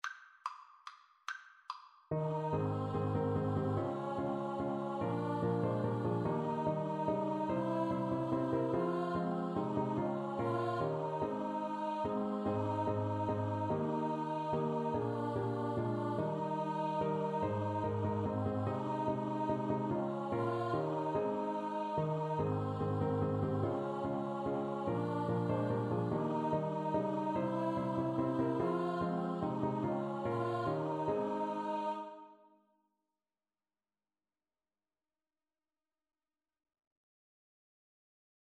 Free Sheet music for Choir (SATB)
3/4 (View more 3/4 Music)